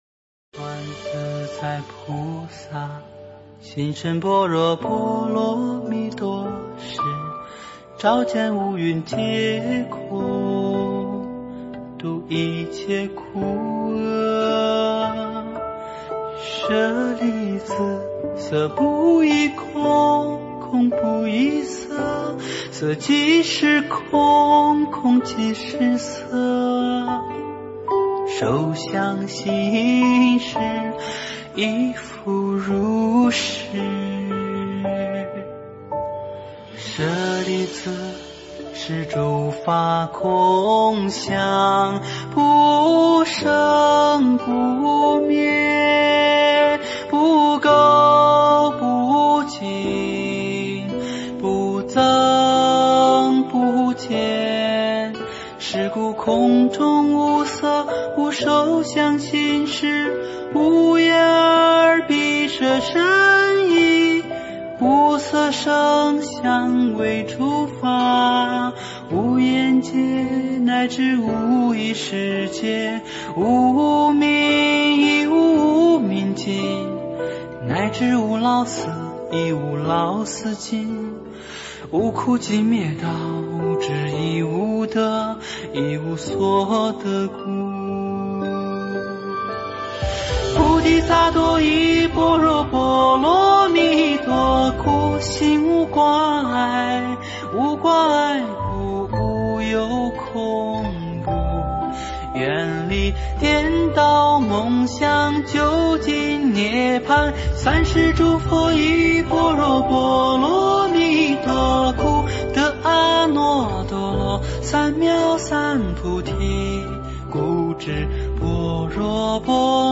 般若波罗密多心经.唱颂
佛音 诵经 佛教音乐 返回列表 上一篇： 法华经-普贤菩萨劝发品第二十八 下一篇： 盂兰盆经 相关文章 流水思幽--古乐心韵 流水思幽--古乐心韵...